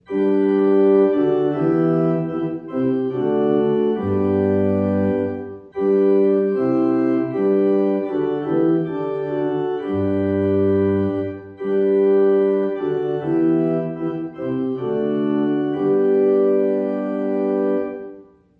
🔊 📄 || G Major